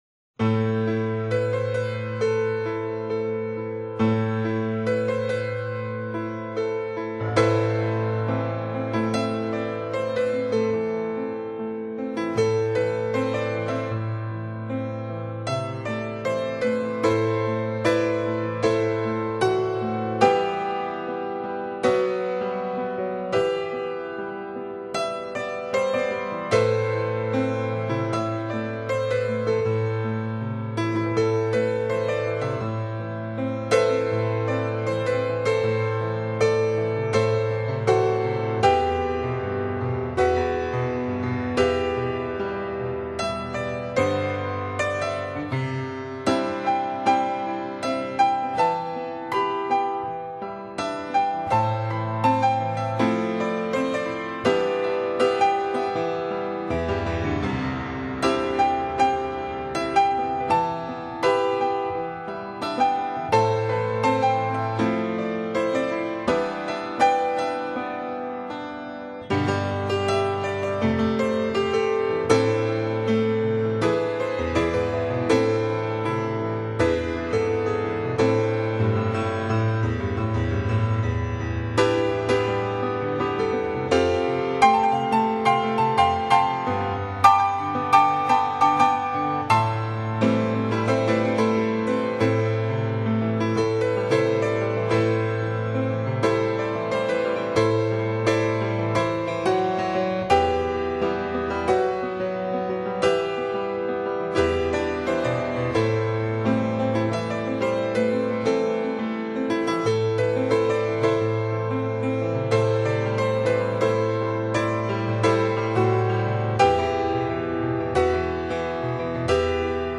此碟是海关没收碟，光碟有部分磨损，拿回来后还没来得及听就压碟并上传，但在传完后才发现十一首以上有轻微跳针现象，